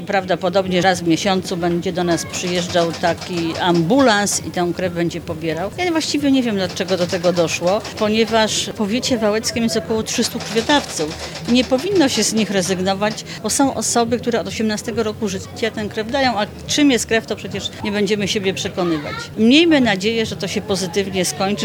– To dla nas niezrozumiała decyzja, wiadomo już jednak, że problem po części uda się rozwiązać – mówi burmistrz Bogusława Towalewska.